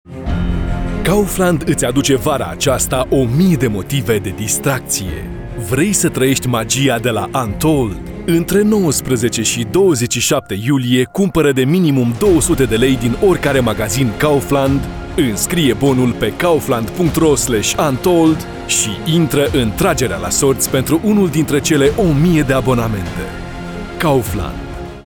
Voix off en Roumain
versatile friendly voice
Commercial